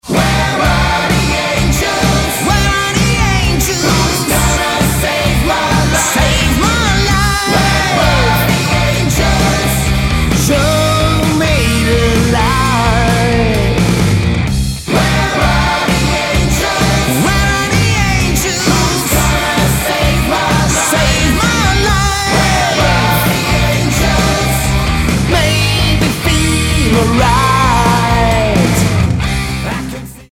Lead Vocals
Guitar
Drums